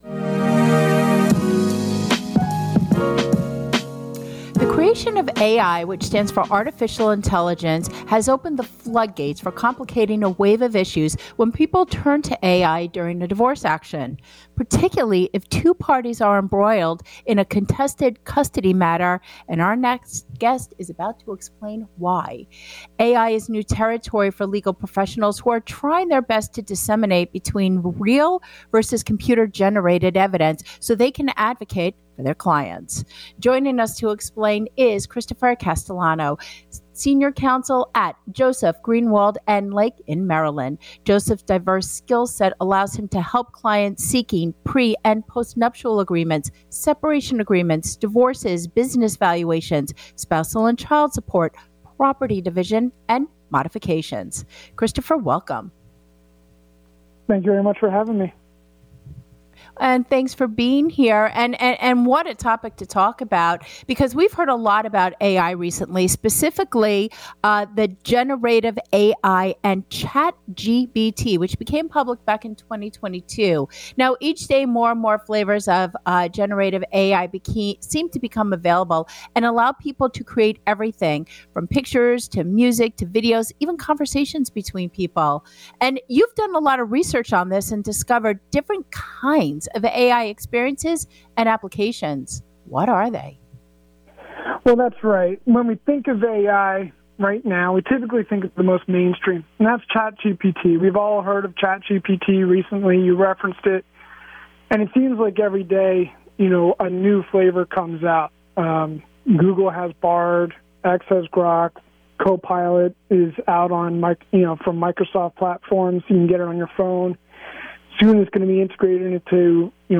The Divorce Hour (Digital Radio Talk show